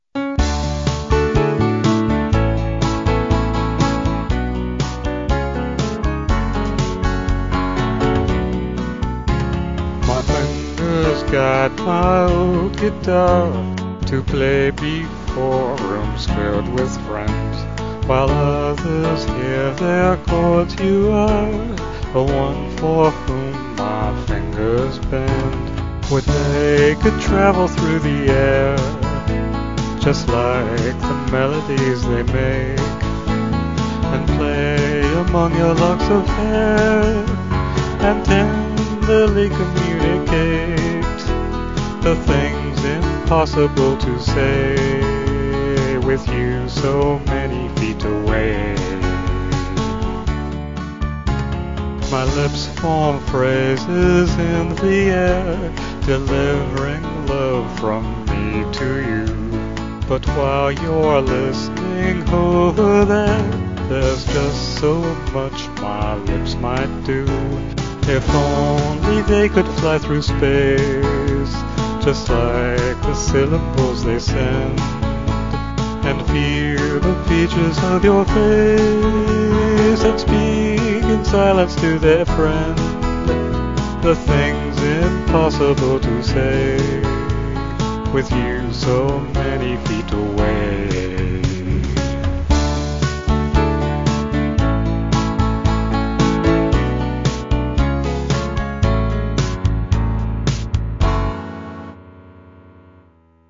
slow 4/4 filk, male or female voice